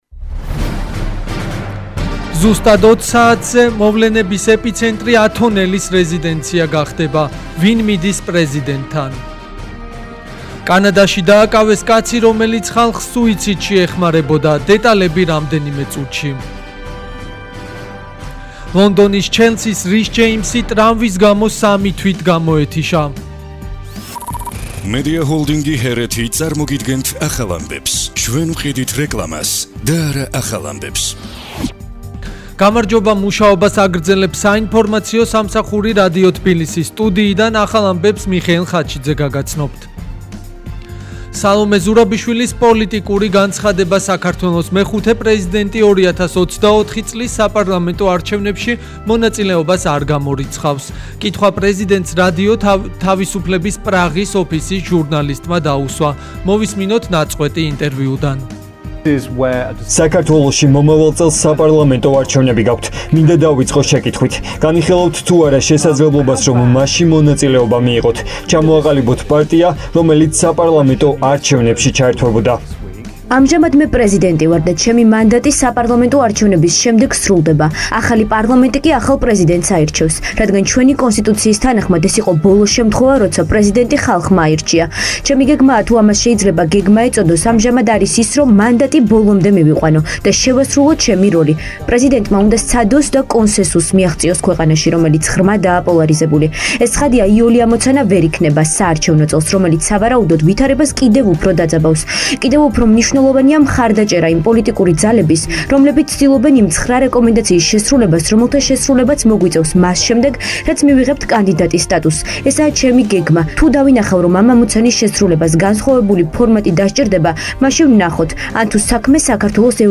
ახალი ამბები 16:00 საათზე